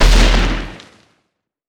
sk_explosion1.wav